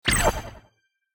tweet_send.ogg